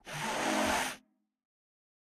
sniffing3.ogg - 25w18a
sniffing3.ogg